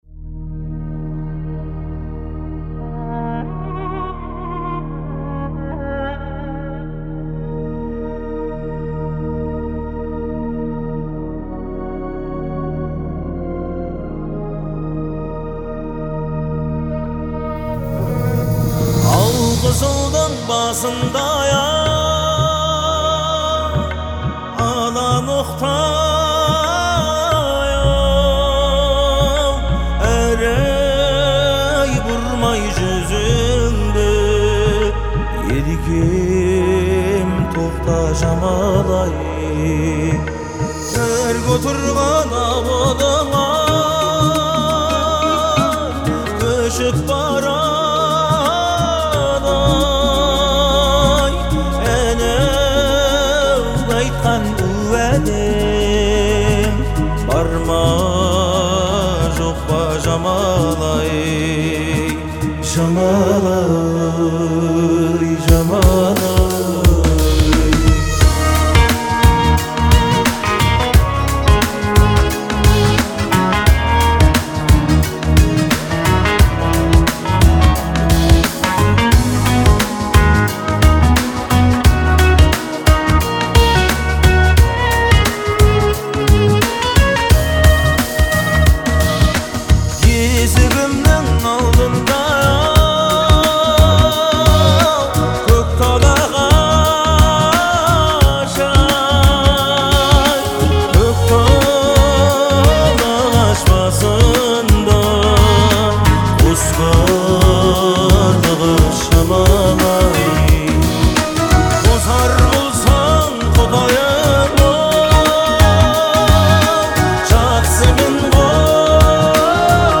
• Жанр: Казахские песни